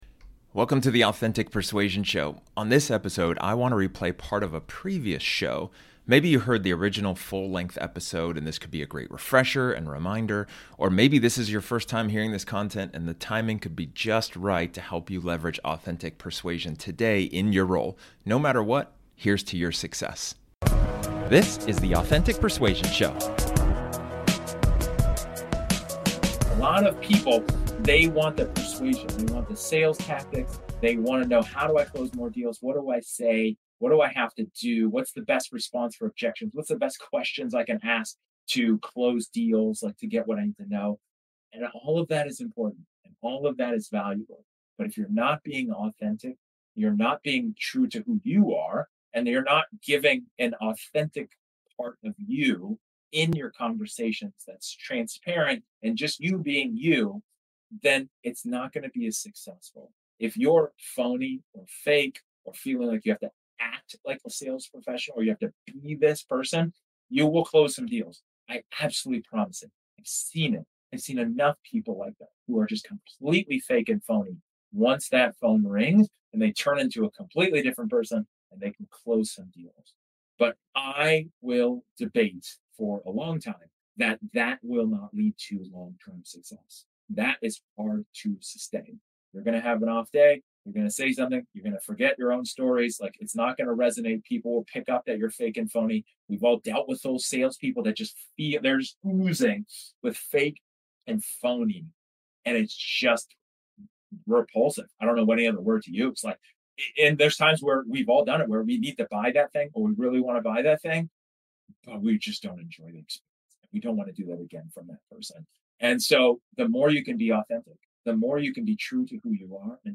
This episode is an excerpt from one of my training sessions where I talk about one of the 5 Sales Success Traits.